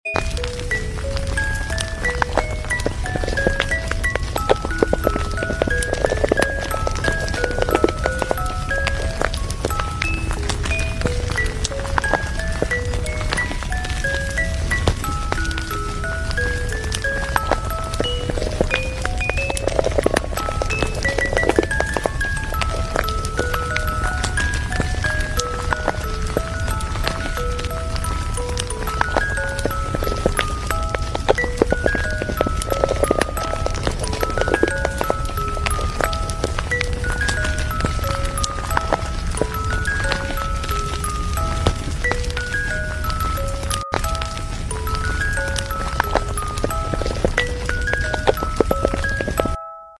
Music box asmr sounds sound effects free download